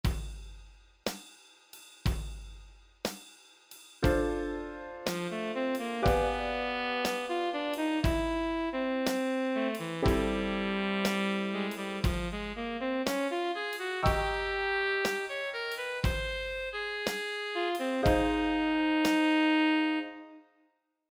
Notice that the last 7 beats present an identical sequenced restatement of beats 2-8 (in the new key).
Looking at the rhythmic flow, we see quite a bit of variety here.  At a slow ballad tempo like this, there is a lot of space.